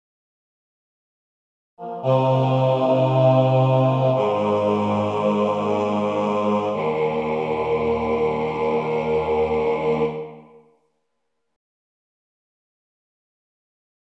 Key written in: E♭ Major
Type: Other male